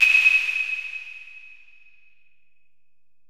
808LP75CLV.wav